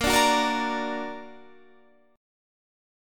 Bb+ Chord
Listen to Bb+ strummed